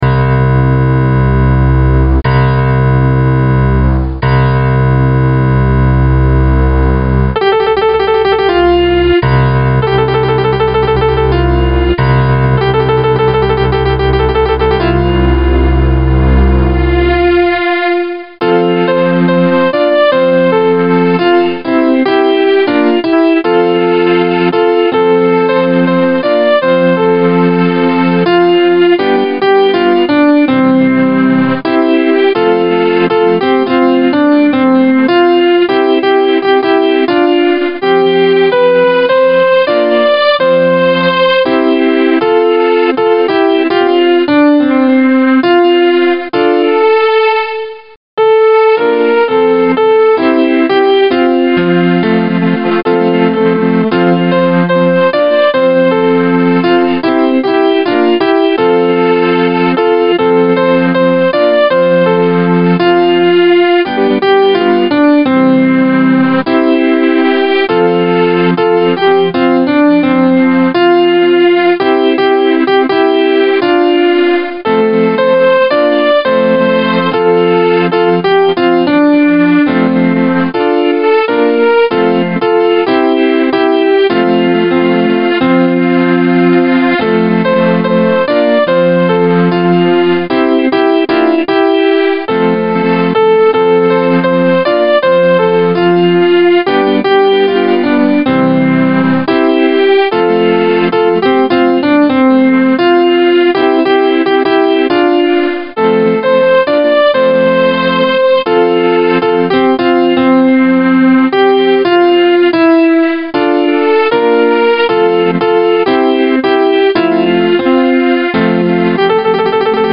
Voice used: Grand piano
Tempo: Moderately spiritual carol